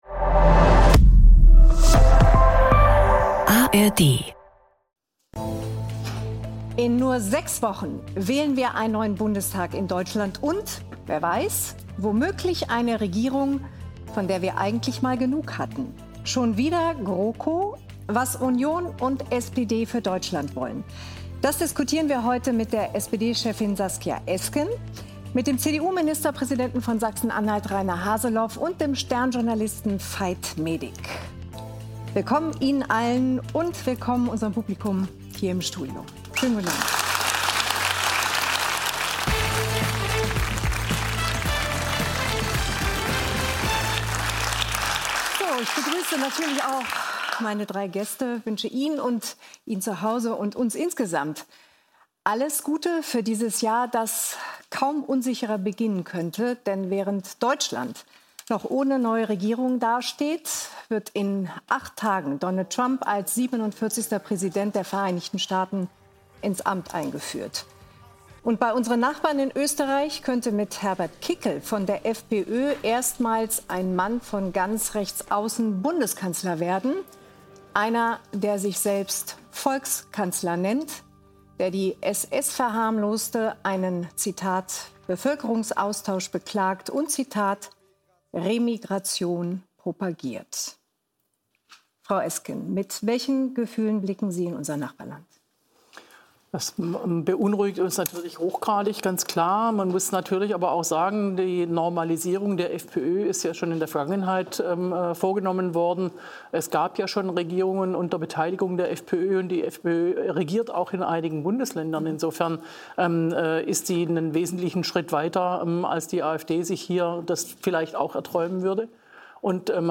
Die ganze Sendung als Audio-Podcast.